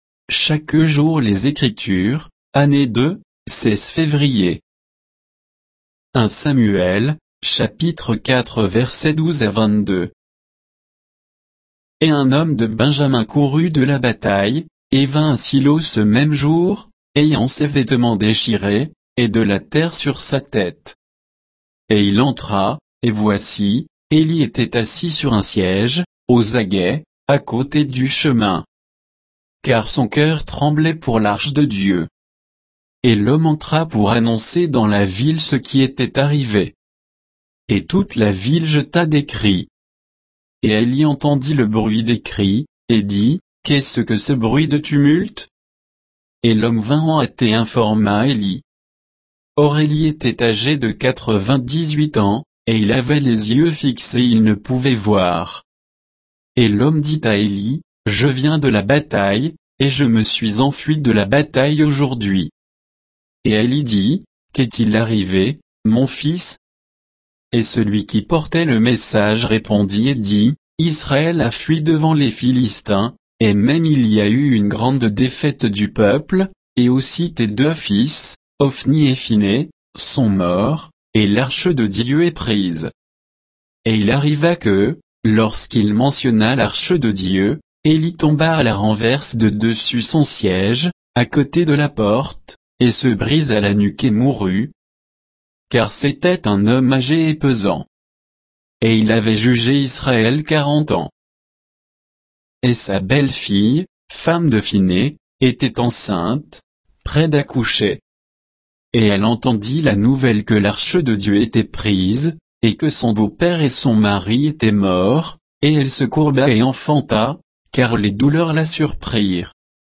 Méditation quoditienne de Chaque jour les Écritures sur 1 Samuel 4, 12 à 22